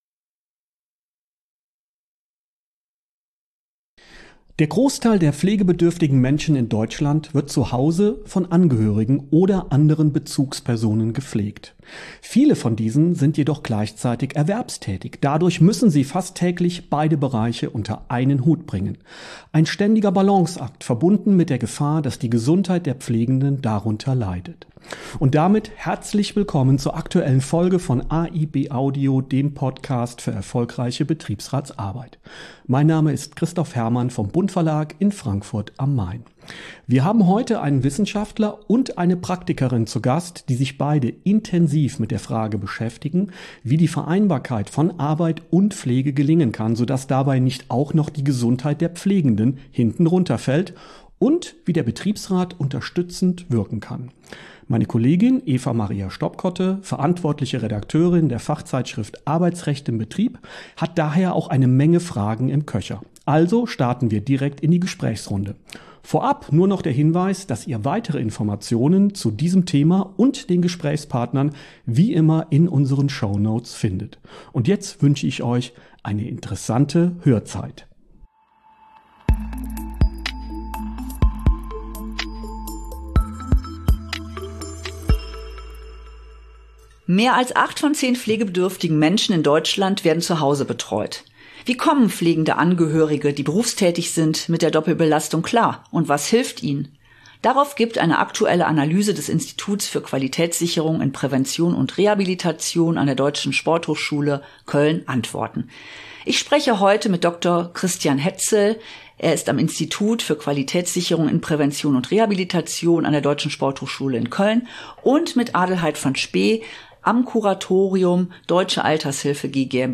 In AiB Audio zeigen Betriebsräte, wie sie sich erfolgreich für ihre Beschäftigten eingesetzt haben und geben anschaulich Tipps zur Umsetzung auch in anderen Betrieben. Namhafte Experten beleuchten aktuelle Trends in Rechtsprechung, Politik und betrieblichen Praxis.
… continue reading 64 Episoden # Bildung # Bund-Verlag # Betriebsrat # Arbeitsrecht # Interviews # Recht # Für Betriebsräte